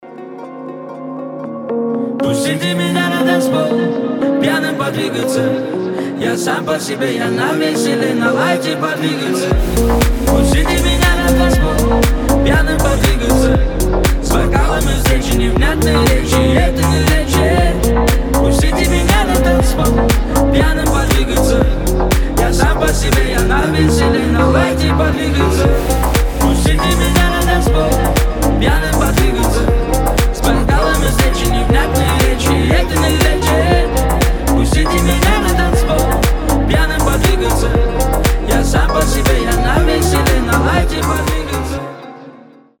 • Качество: 320, Stereo
гитара
deep house